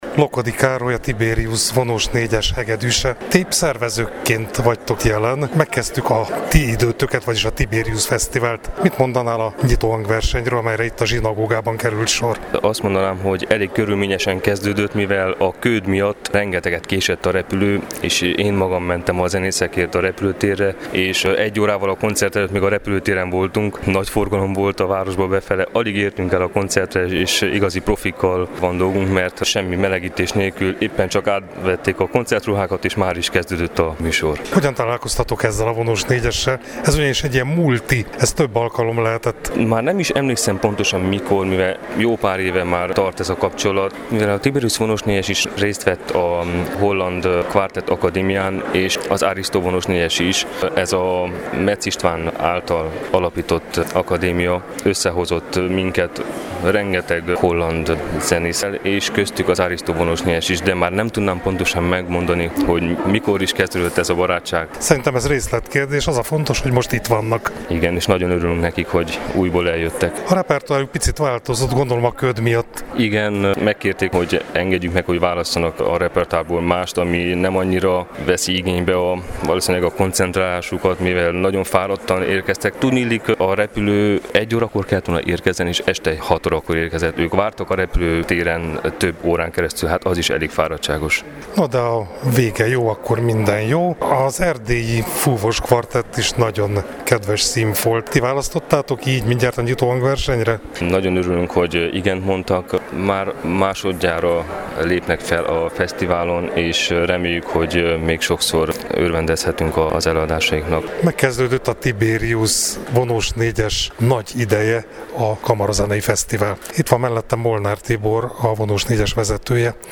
Vasárnap este, kalandosan ugyan, de elstartolt az idei Tiberius kamarazenei fesztivál a marosvásárhelyi zsinagógában.